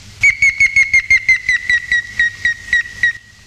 Pic cendr�, picus canus
pic-cendre.mp3